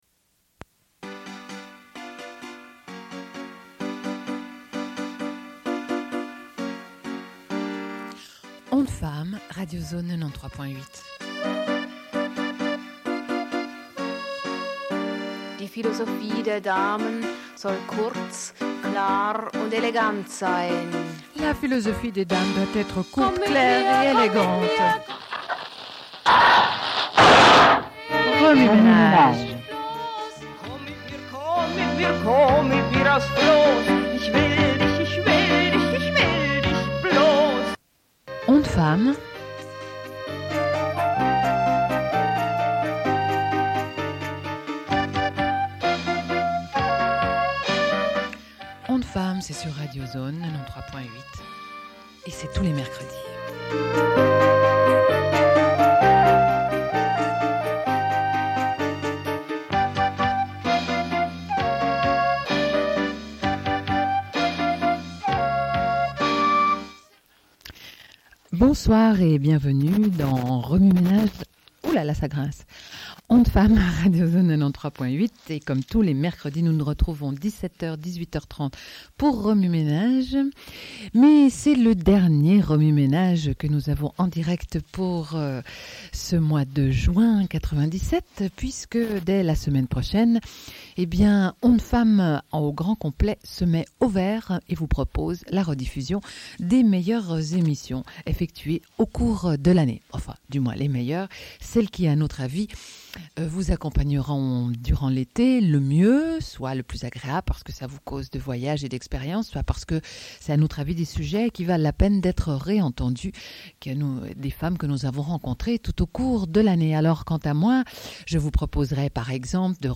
Une cassette audio, face A
Radio Enregistrement sonore